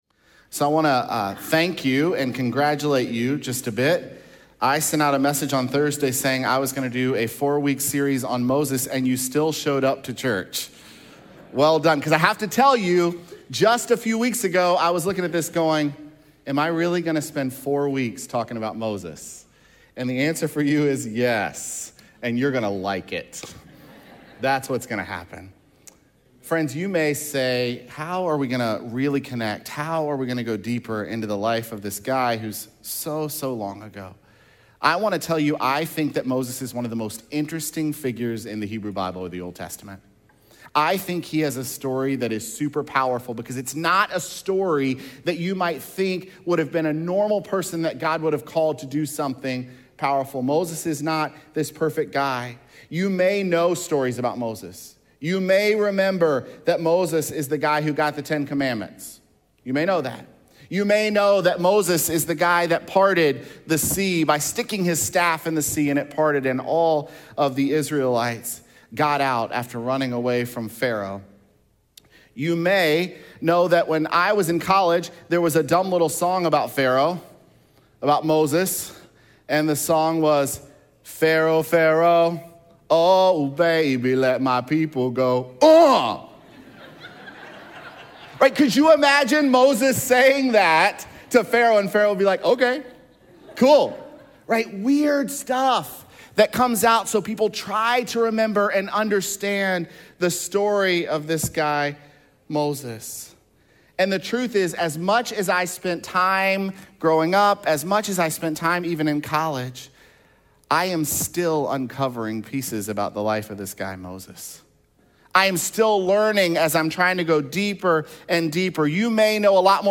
aug6sermon.mp3